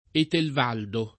vai all'elenco alfabetico delle voci ingrandisci il carattere 100% rimpicciolisci il carattere stampa invia tramite posta elettronica codividi su Facebook Etelvoldo [ etelv 0 ldo ] o Etelvaldo [ etelv # ldo ] pers. m. stor.